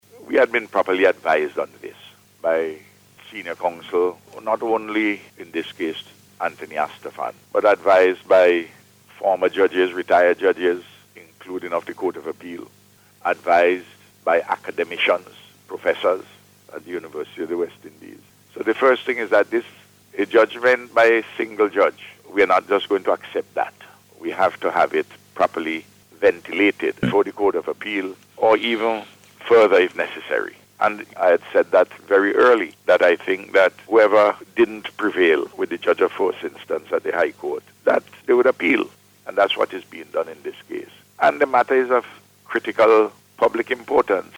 Speaking on NBC’s Face to Face programme this morning, Prime Minister Gonsalves said the Government is satisfied that the actions which it took when COVID-19 was rampant, were correct.